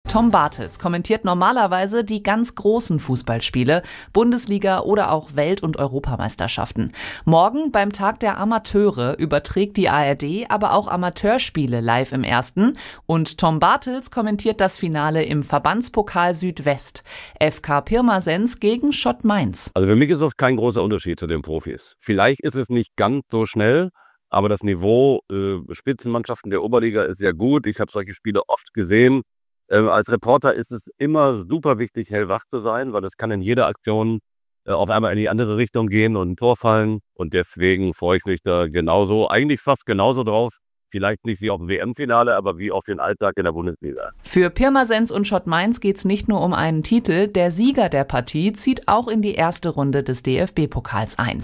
Sport-Reporter Tom Bartels wird das Spiel Pirmasens gegen Mainz kommentieren.